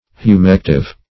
humective - definition of humective - synonyms, pronunciation, spelling from Free Dictionary
Humective \Hu*mec"tive\, a.